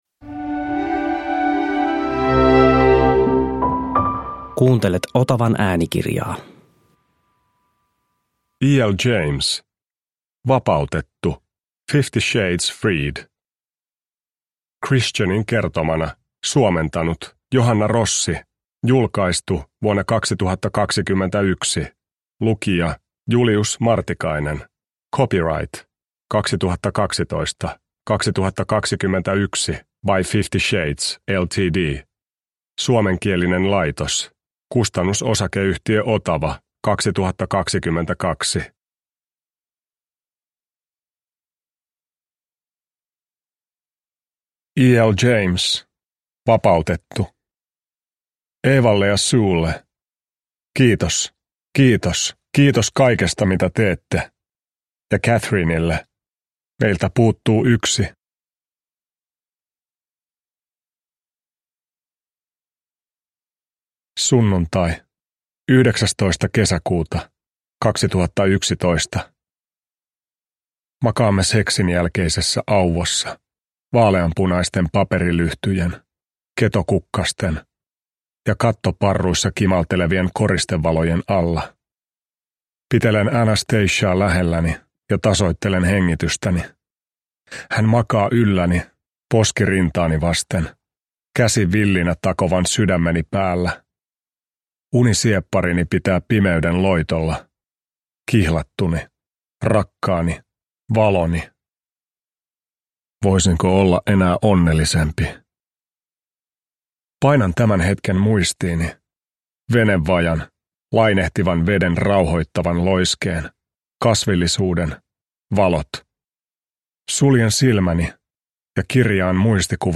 Vapautettu (ljudbok) av E L James